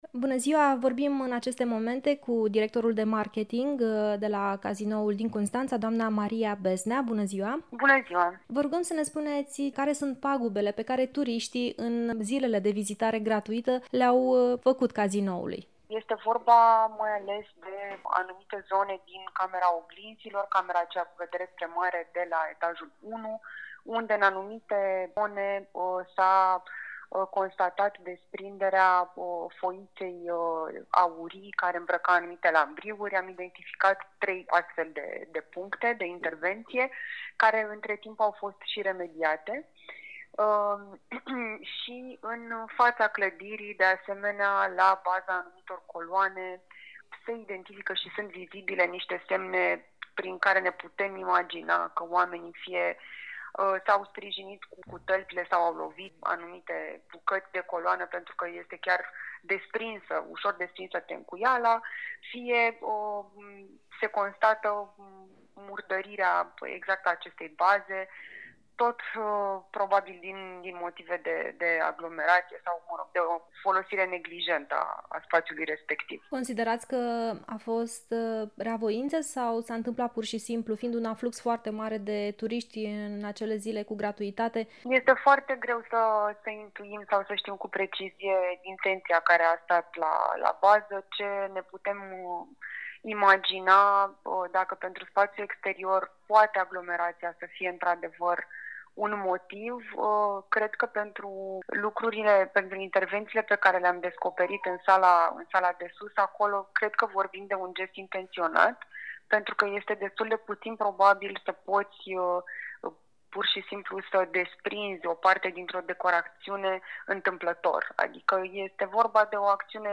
într-un interviu pentru Radio Constanța